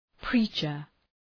Προφορά
{‘pri:tʃər}